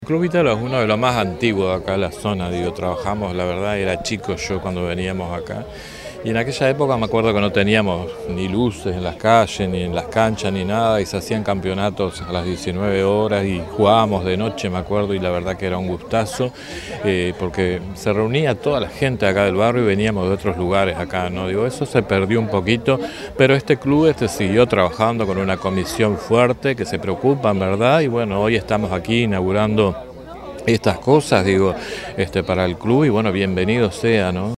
alcalde_del_municipio_de_barros_blancos_julian_rocha.mp3